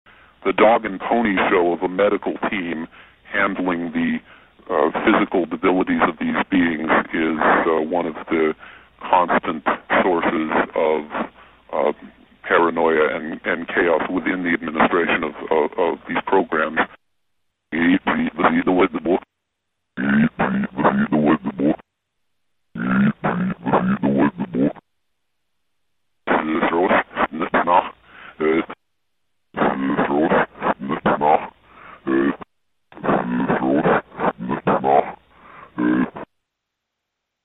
最大の難点は、 ヴォイスチェンジャーによって音声が聞きづらくなっていたことだった。
(mp3) というリバース・スピーチが現れていた。